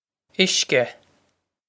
Uisce Ish-ka
Pronunciation for how to say
This is an approximate phonetic pronunciation of the phrase.